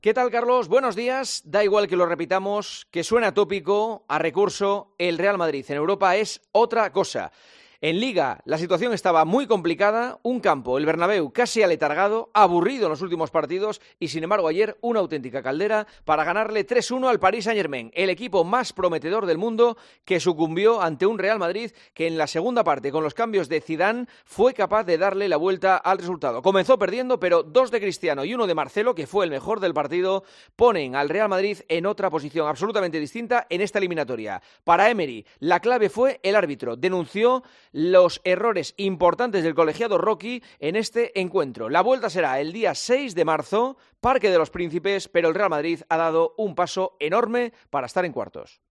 Escucha el comentario del director de "El Partidazo de COPE', Juanma Castaño, en 'Herrera en COPE'